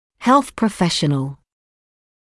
[helθ prə’feʃənl][хэлс прэ’фэшэнл]медицинский работник